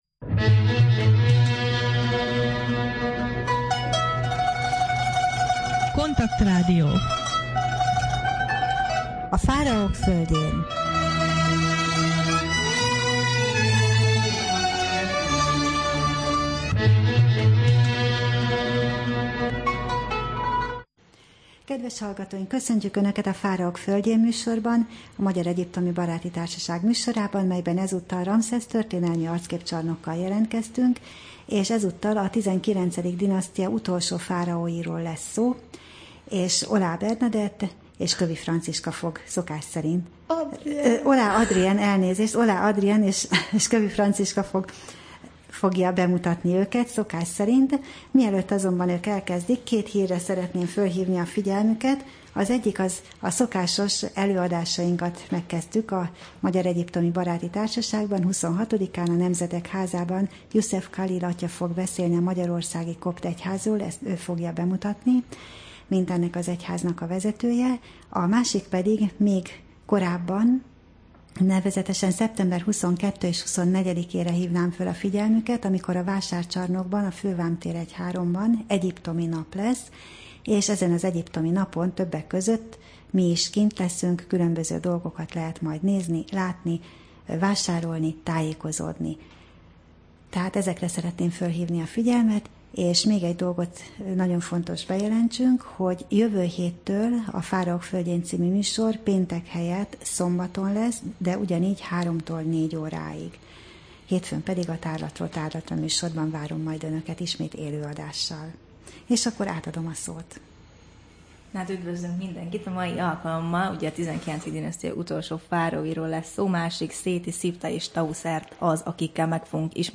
Rádió: Fáraók földjén Adás dátuma: 2011, Szeptember 16 Ramszesz történelmi arcképcsarnok / KONTAKT Rádió (87,6 MHz) 2011 szeptember 16.